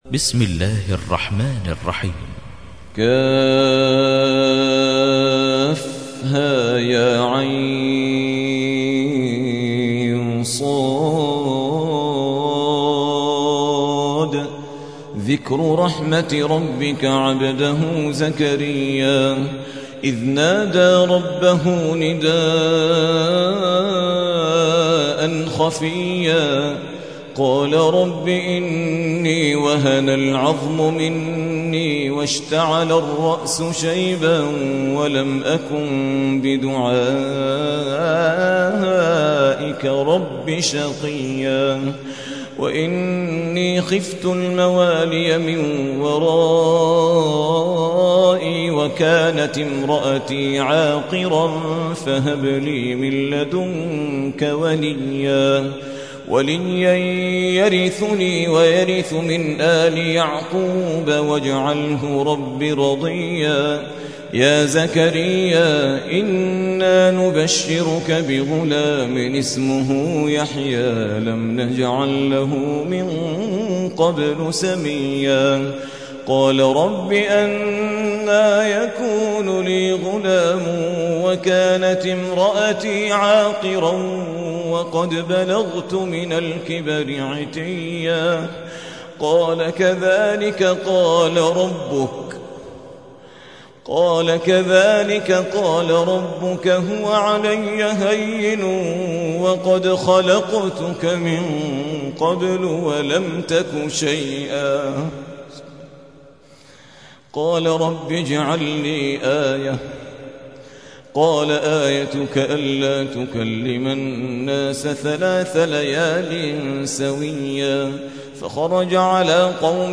تحميل : 19. سورة مريم / القارئ عادل ريان / القرآن الكريم / موقع يا حسين